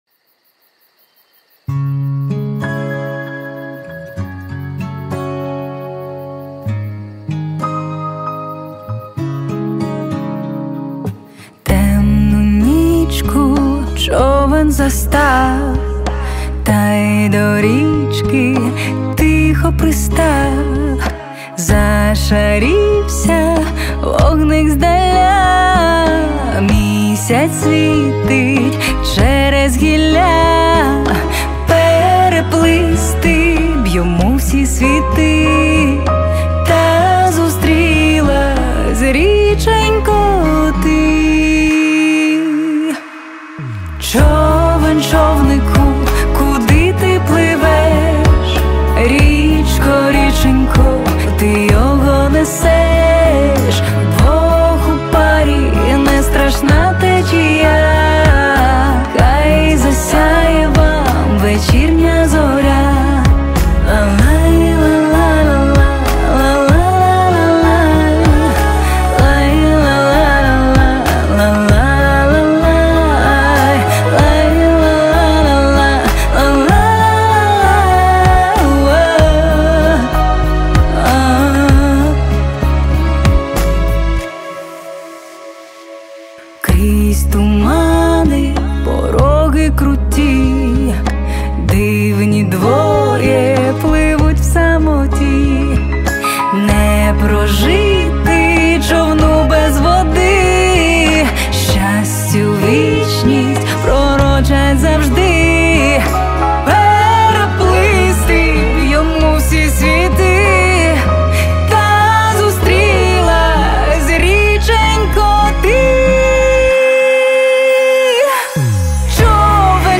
zozulya_choven_ukrainska_obryadova_meloua_.mp3